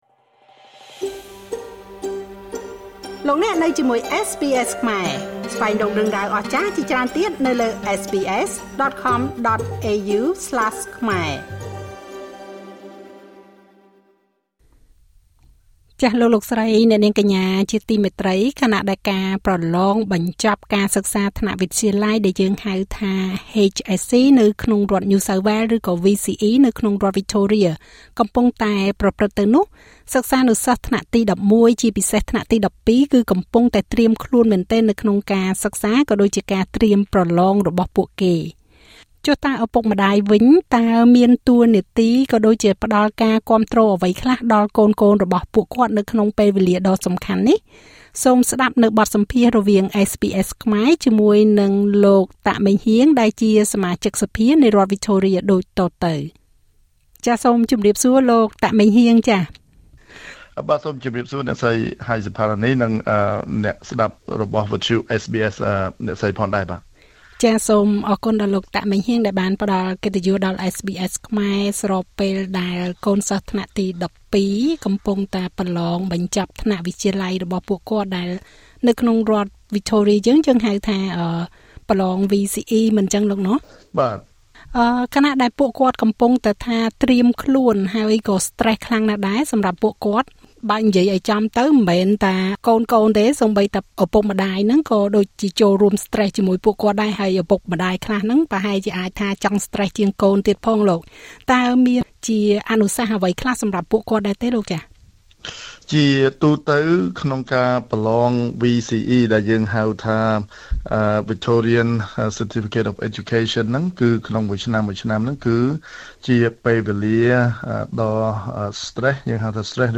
ចុះចំណែកឯឳពុកម្តាយវិញ តើពួកគាត់គួរផ្តល់ការគាំទ្រអ្វីខ្លះដល់កូនៗ និងចៀសវាងការដាក់សម្ពាធបន្ថែមលើពួកគេបែបណាខ្លះ? សូមស្តាប់អនុសាសន៍របស់លោក តាក ម៉េងហ៊ាង សមាជិកសភានៃរដ្ឋវិចថូរៀដូចតទៅ។